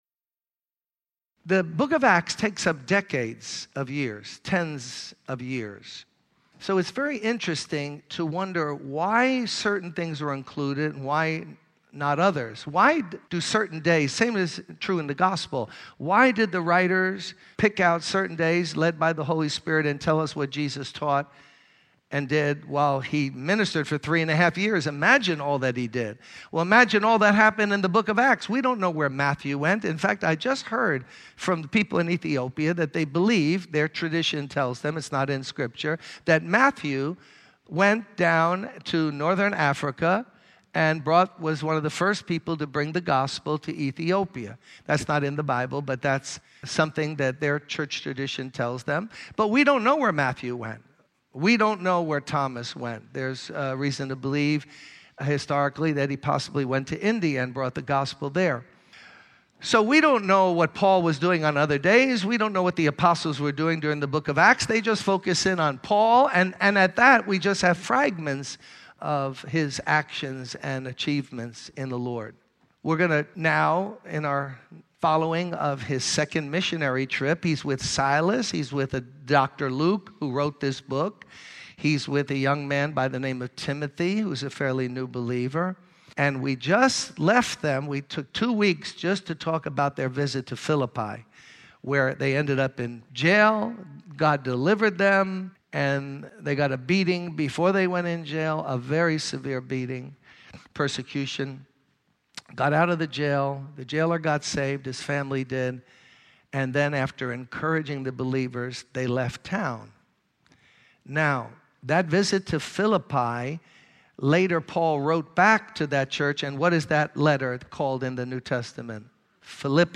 In this sermon, the speaker emphasizes the importance of believers being filled with the love of God. He uses the analogy of a mother tenderly caring for her newborn baby to illustrate the kind of love and compassion believers should have towards others.